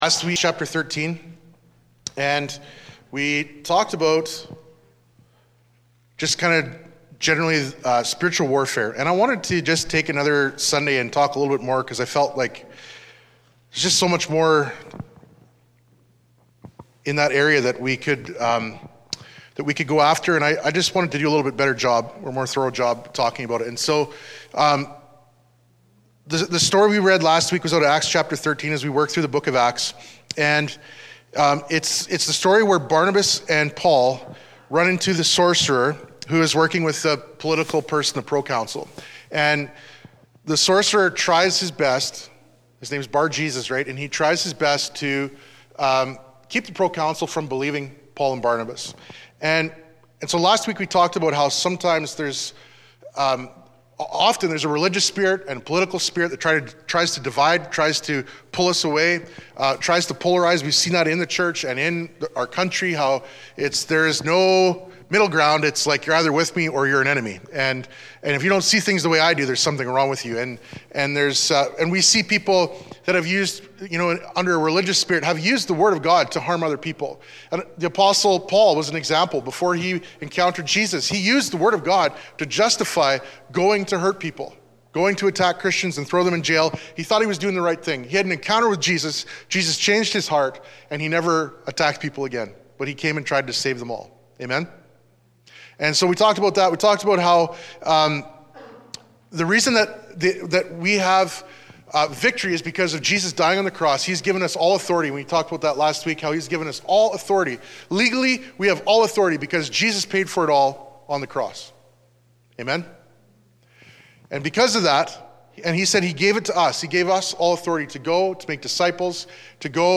Sermons | Family Church in Maple Creek